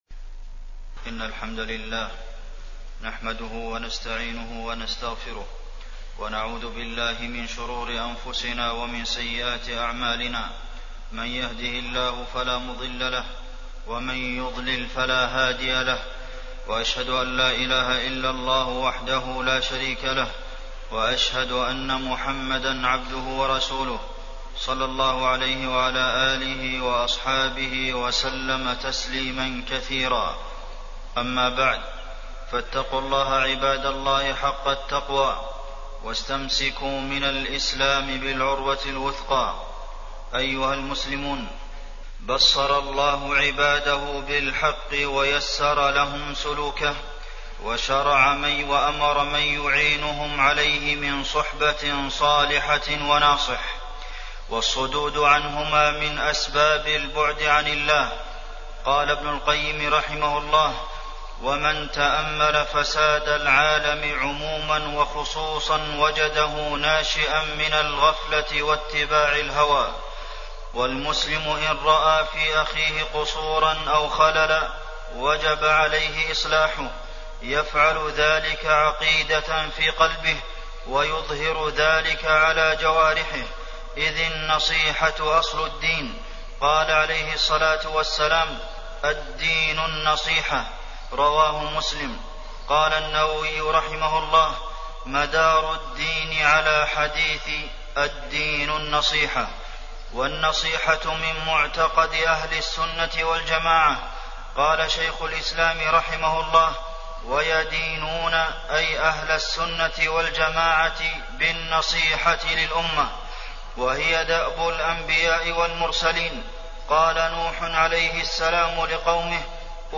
تاريخ النشر ٢٣ ربيع الأول ١٤٣٠ هـ المكان: المسجد النبوي الشيخ: فضيلة الشيخ د. عبدالمحسن بن محمد القاسم فضيلة الشيخ د. عبدالمحسن بن محمد القاسم النصيحة